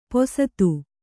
♪ posatu